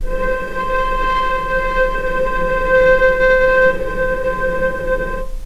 healing-soundscapes/Sound Banks/HSS_OP_Pack/Strings/cello/ord/vc-C5-pp.AIF at 48f255e0b41e8171d9280be2389d1ef0a439d660
vc-C5-pp.AIF